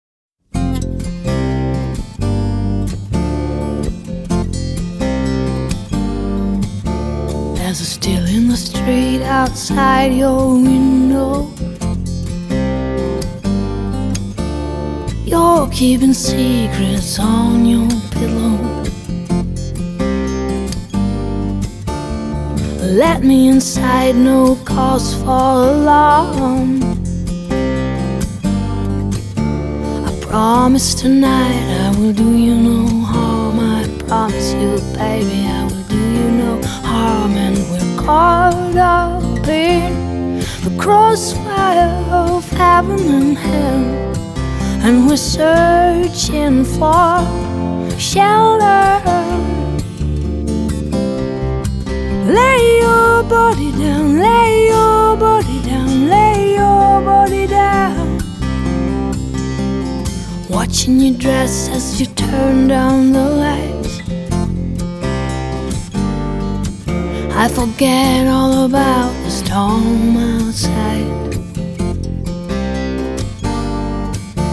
發燒天碟